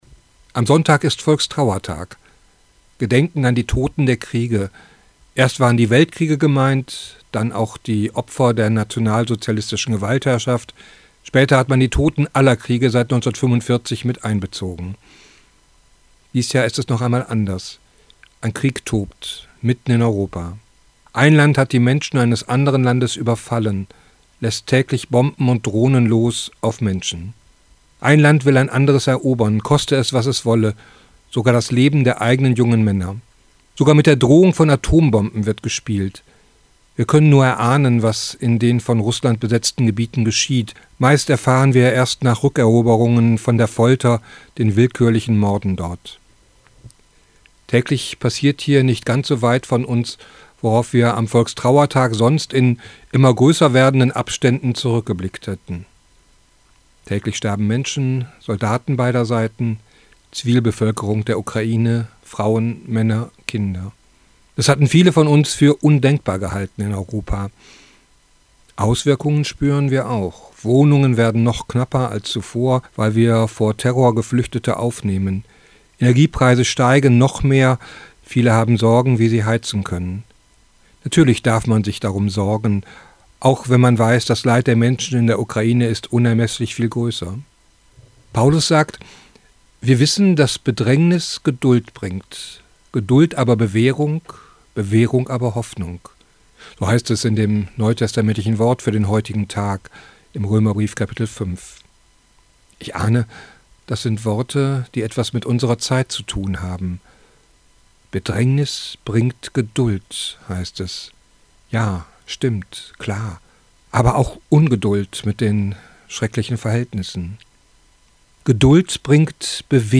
Radioandacht vom 11. November